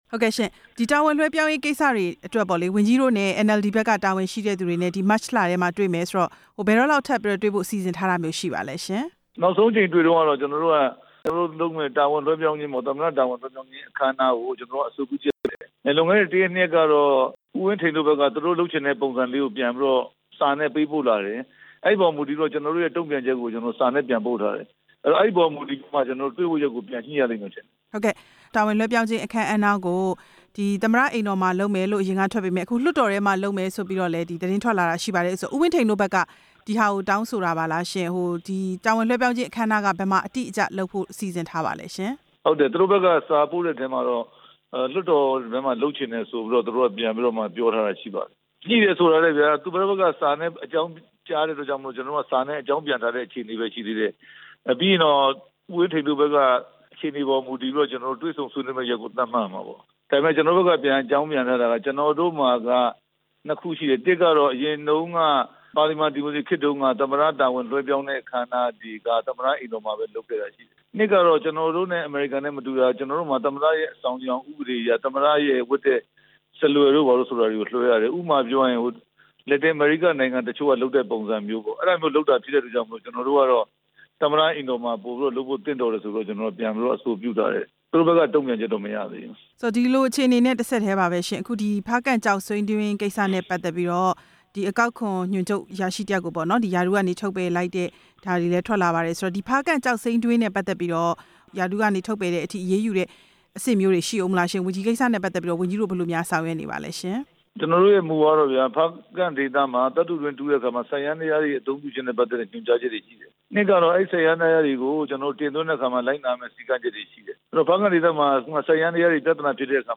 ပြန်ကြားရေးဝန်ကြီး ဦးရဲထွဋ်နဲ့ မေးမြန်းချက်